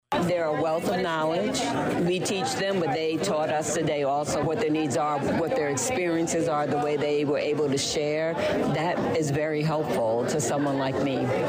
(ABOVE) Congresswoman Robin Kelly leads a roundtable discussion with Danville High School students focused on gun violence.